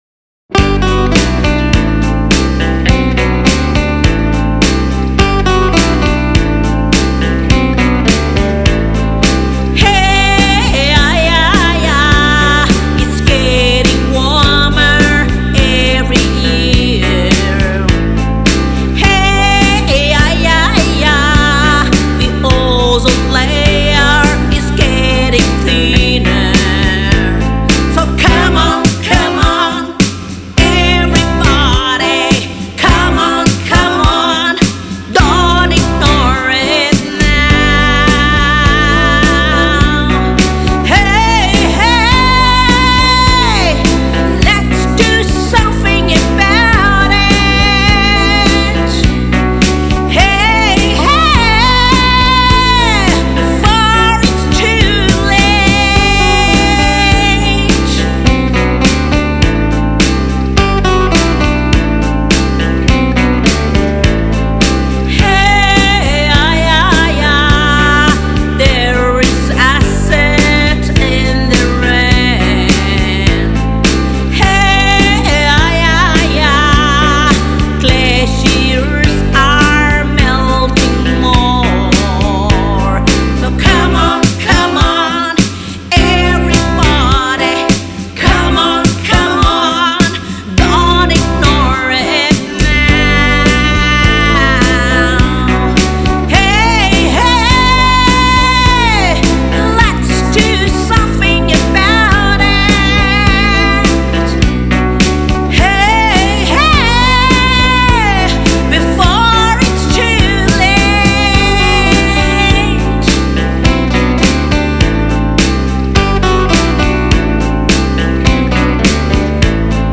Naga folk-fusion band